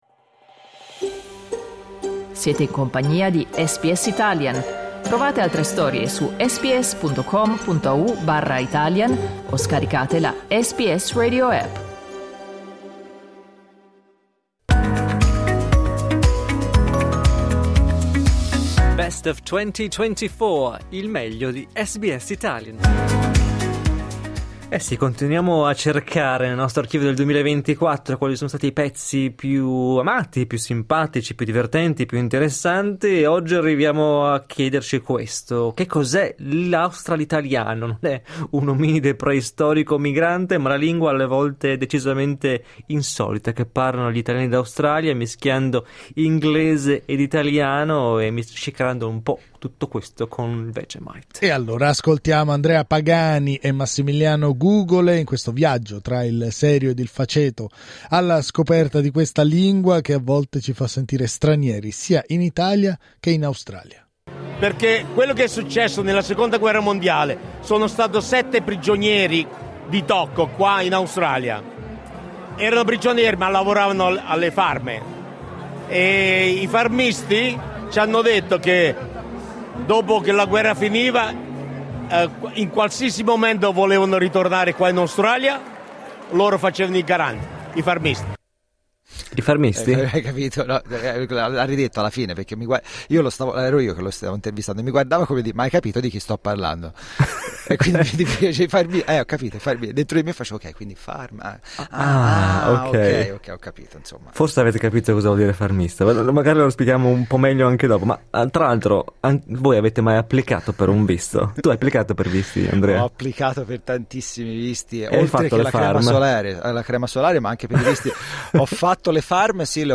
Ascolta il nostro dibattito cliccando il tasto 'play' in alto a sinistra QUI IL PODCAST/ARTICOLO ORIGINALE Australitaliano, punto d'incontro tra due lingue e due identità SBS Italian 25:29 Italian Ascolta SBS Italian tutti i giorni, dalle 8am alle 10am.